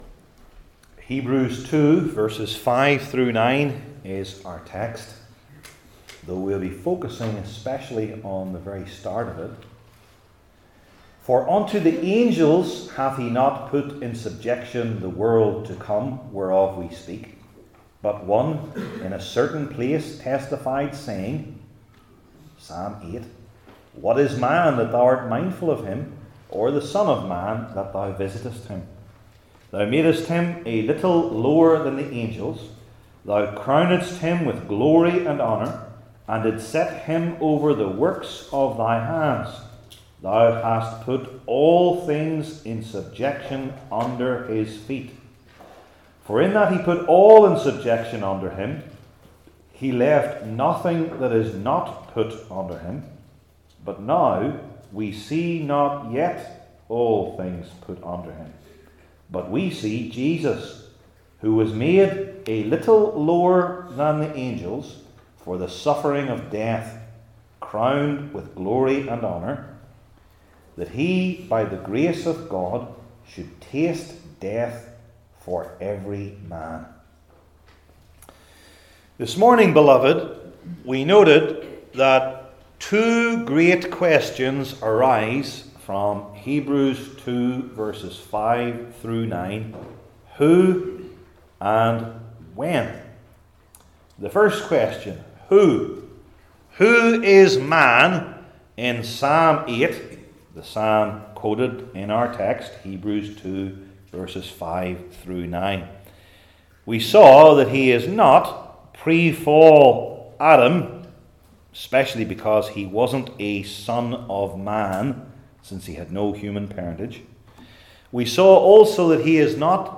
New Testament Sermon Series I. A Jewish Millennium?